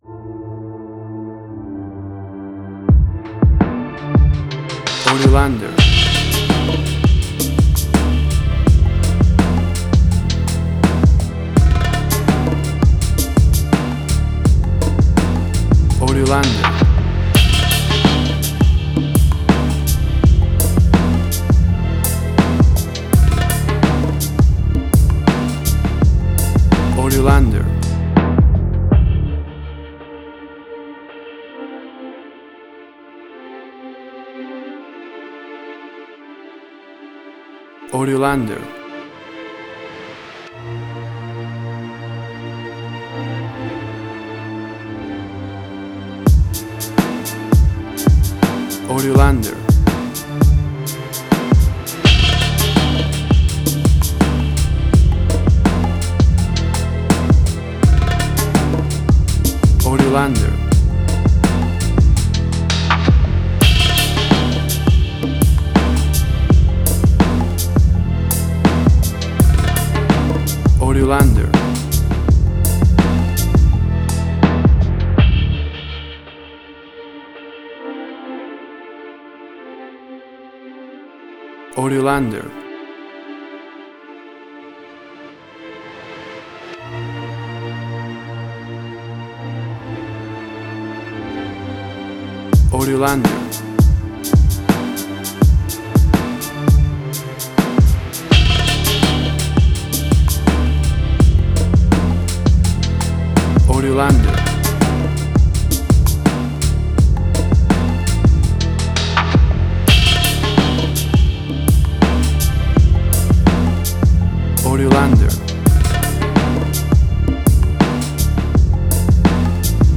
Tempo (BPM): 83